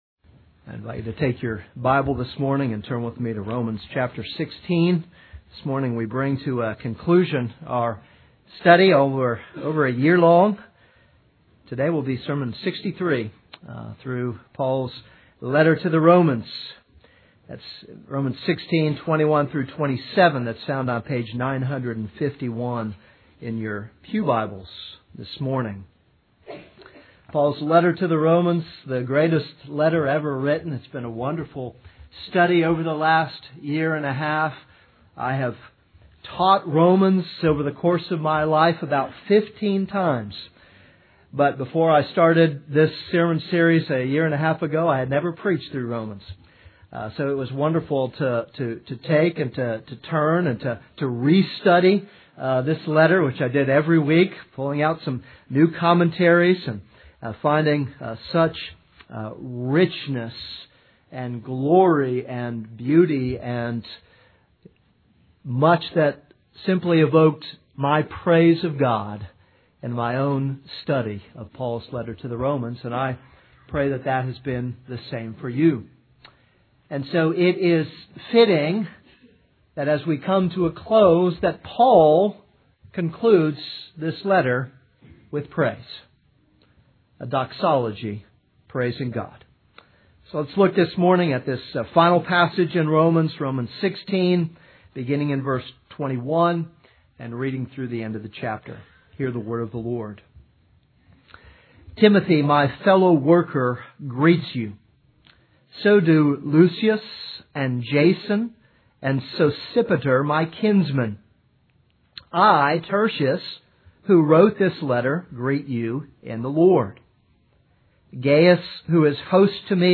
This is a sermon on Romans 16:21-27.